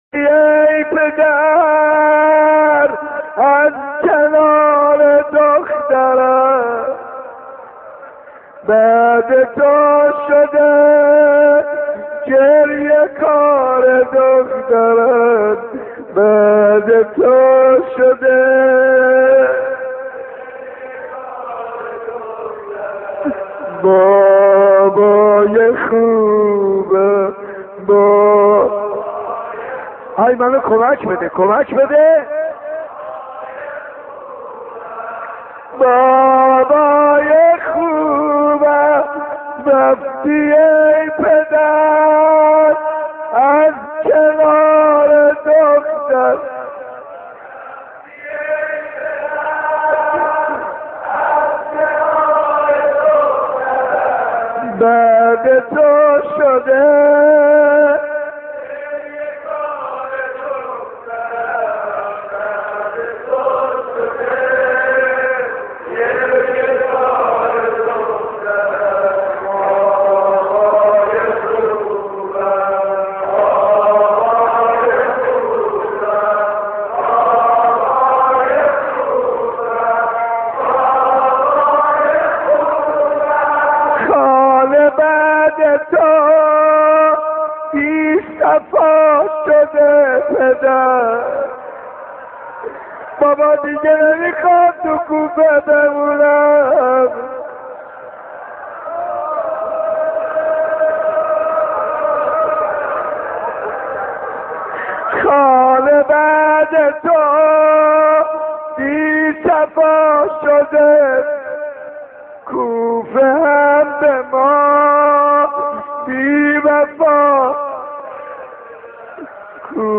دانلود مداحی بعد تو شده گریه کار دخترت بابای خوبم - دانلود ریمیکس و آهنگ جدید
مرثیه زبانحال حضرت فاطمه(س) با پیامبر(ص) با نوای حاج منصور ارضی (7:29)